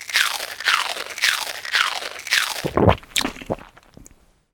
scarf.ogg